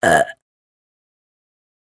女性のゲップ 着信音